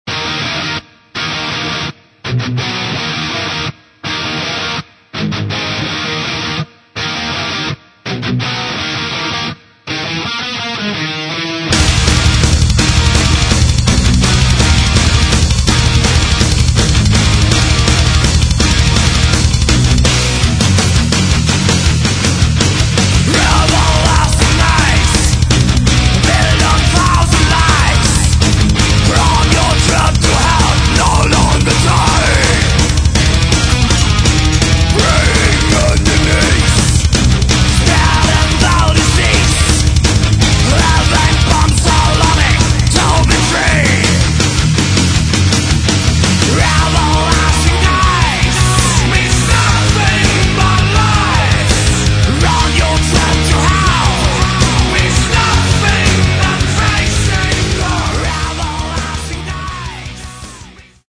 Metal
Тем не менее, все подается гораздо живее и разнообразнее.